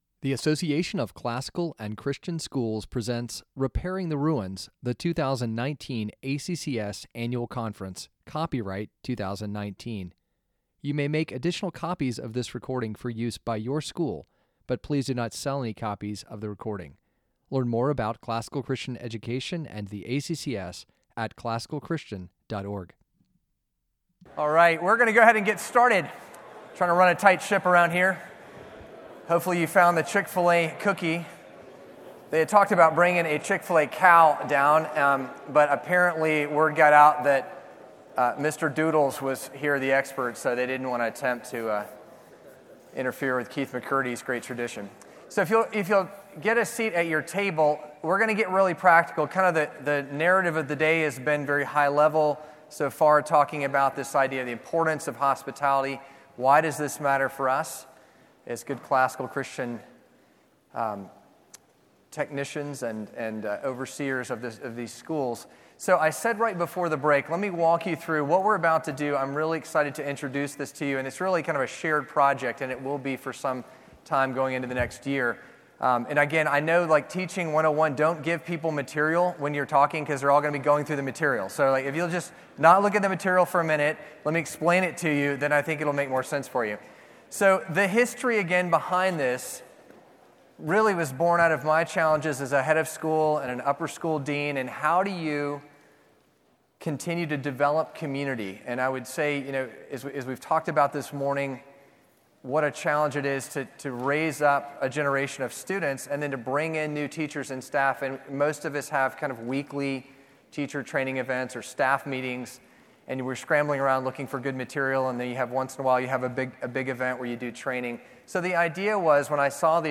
2019 Leaders Day Talk | 18:12 | Training & Certification